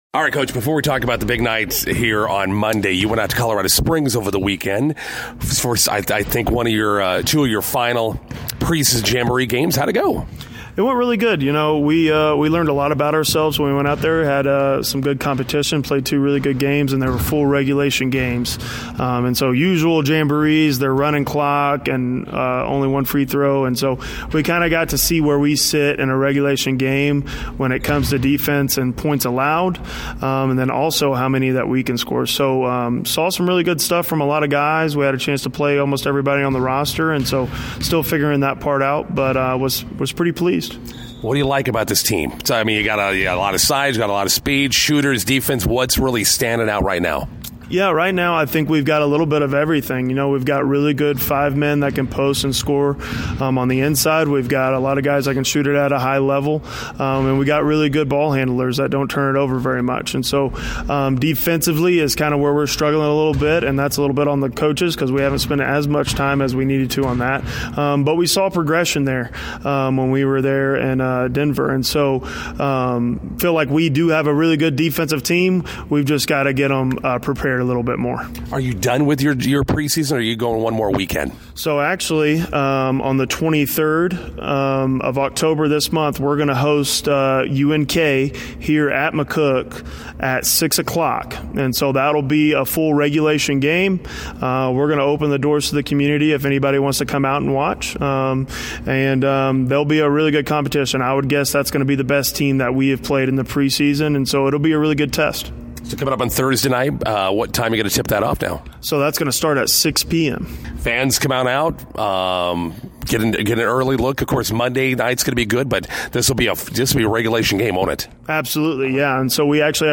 INTERVIEW: McCook Community College men's basketball hosting McCook Madness tonight at the Graff Events Center.